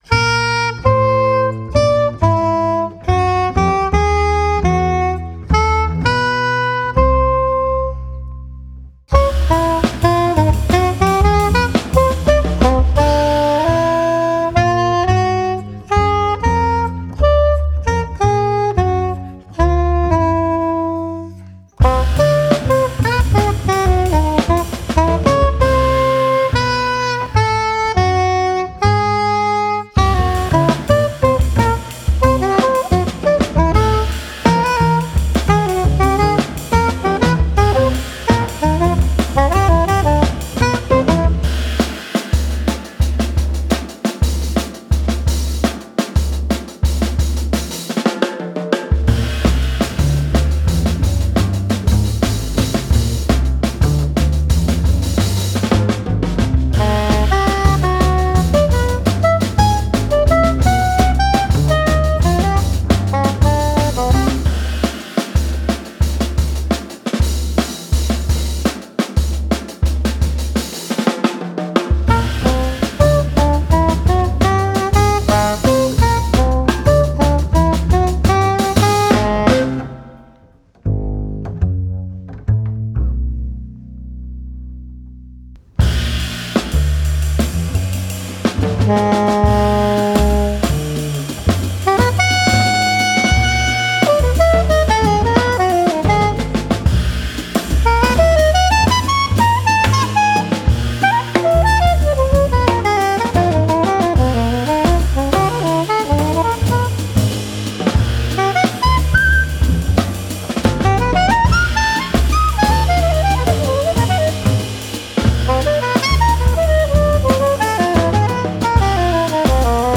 contrabbasso
batteria